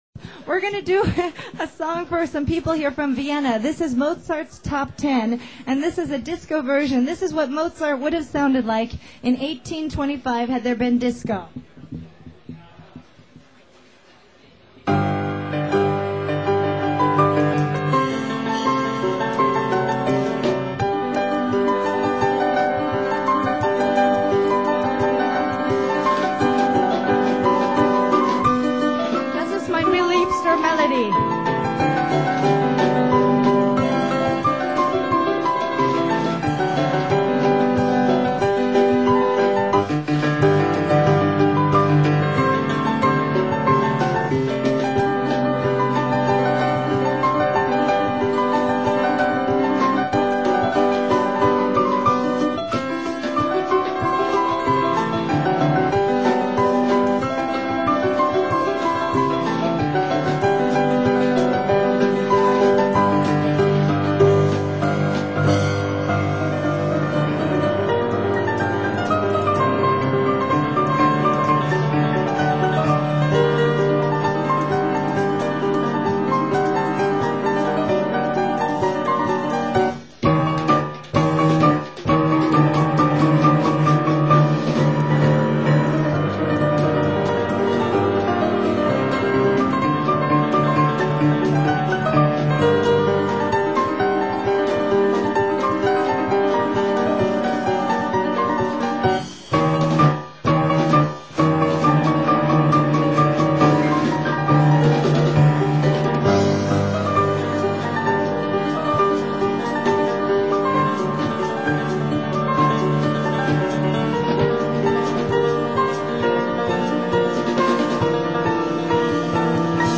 서정적이면서도 그 웅장함과 일말의 모든 기교를 소화해내는